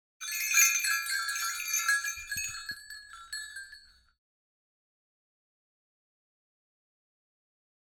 دانلود آهنگ زنگوله شتر 2 از افکت صوتی انسان و موجودات زنده
جلوه های صوتی
دانلود صدای زنگوله شتر 2 از ساعد نیوز با لینک مستقیم و کیفیت بالا